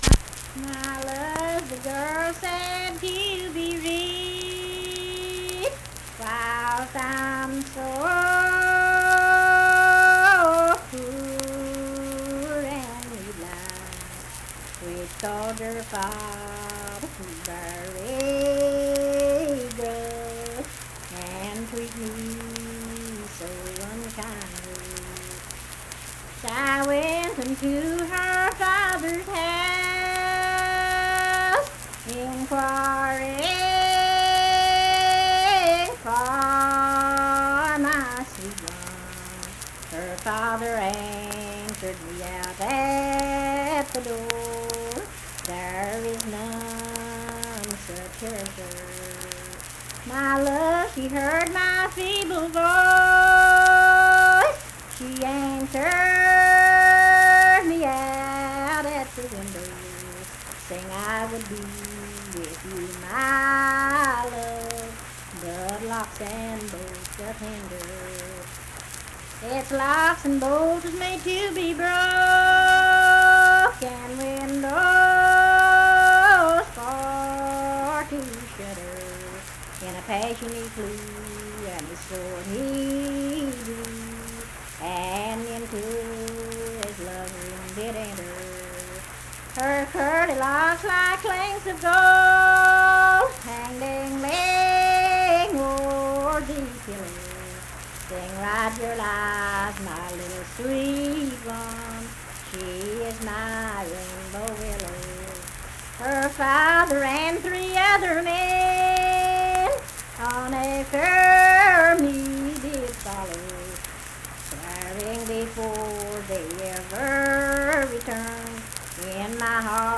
Unaccompanied vocal music
Verse-refrain, 7(4).
Voice (sung)